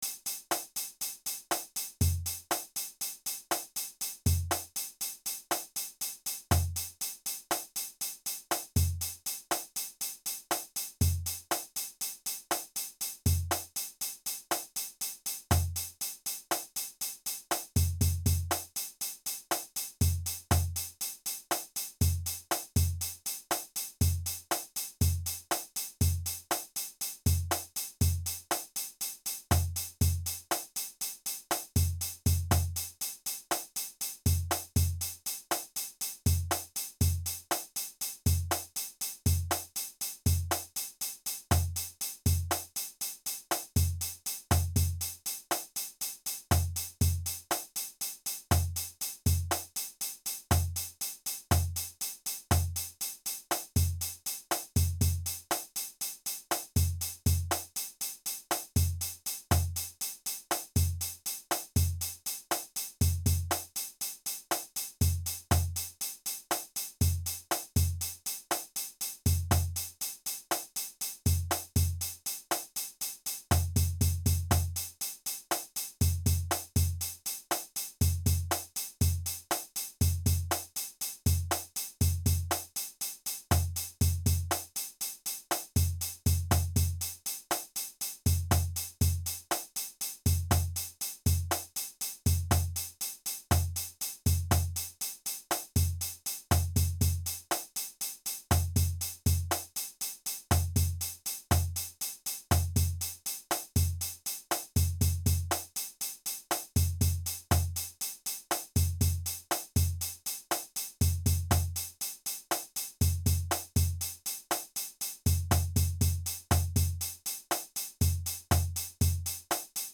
‼ Eighth-Note Groove Study:
All 256 Bass Drum Eighth-Note Grooves
256grooves.mp3